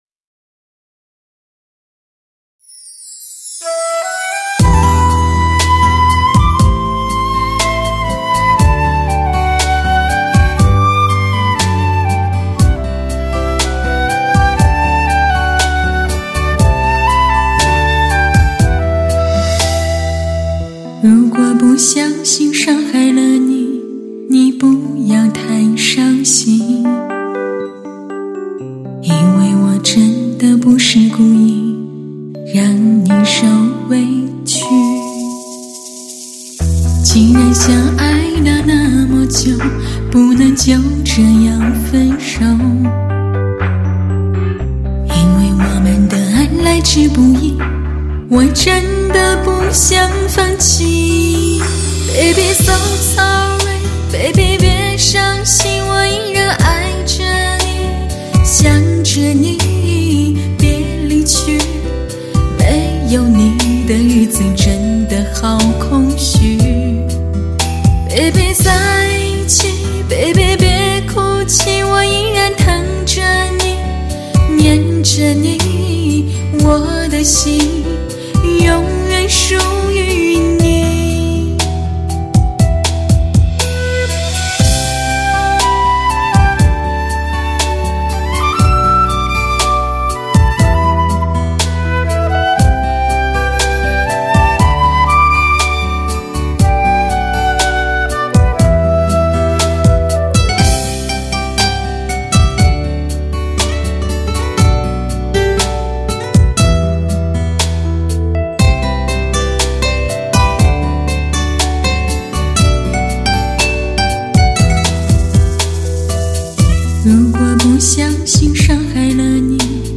真是一路上听不释手的HI-FI靓声啊。